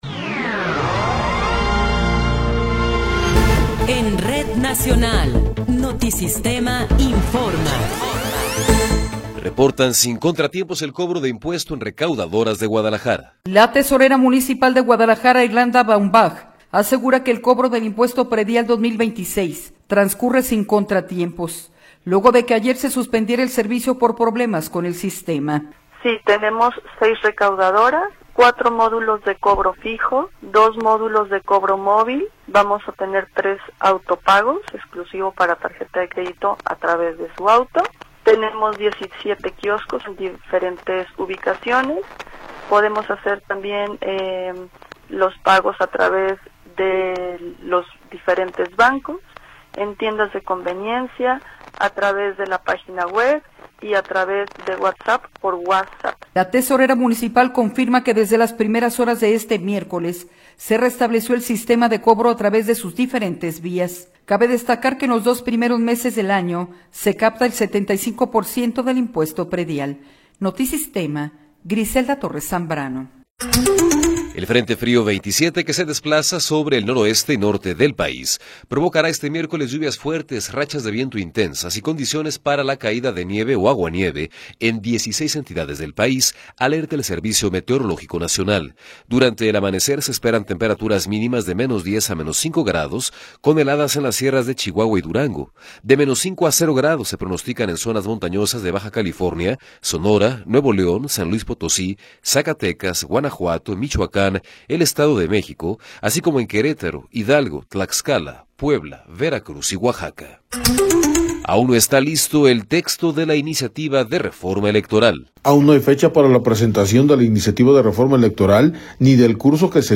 Noticiero 12 hrs. – 7 de Enero de 2026